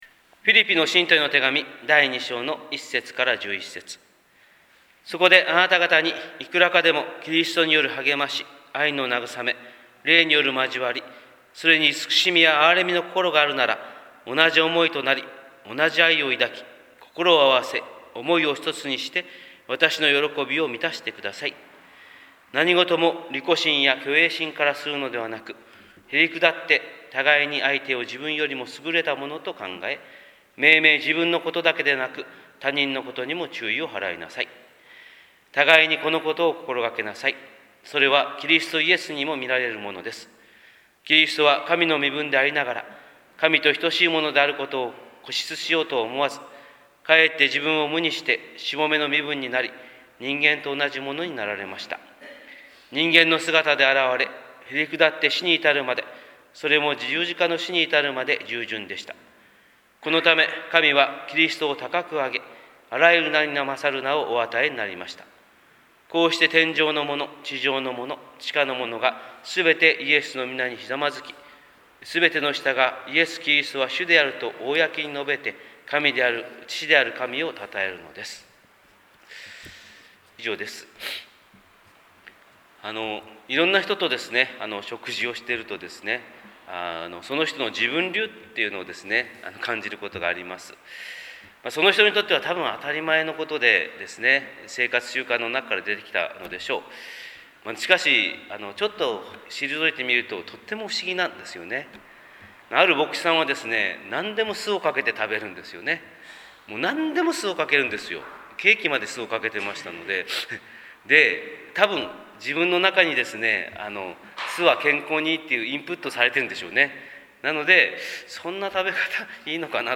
日本福音ルーテル教会（キリスト教ルター派）牧師の朝礼拝説教です！